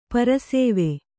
♪ para sēve